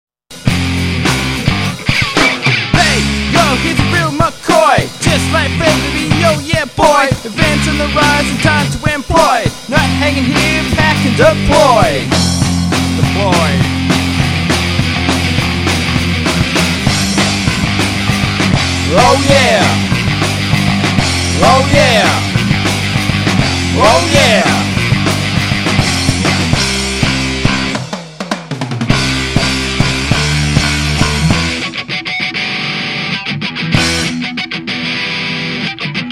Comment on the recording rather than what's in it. Good quality and recorded by digital multitrack.